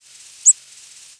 Ovenbird Seiurus aurocapillus
Flight call description An explosive, piercing, slightly rising "seet" with a faintly sibilant quality. Sometimes a more abrupt "pseek".
Fig.1. Maryland October 1, 1994 (MO).
Bird in flight with Eastern Towhee calling in the background.
The frequency track was single-banded and variable.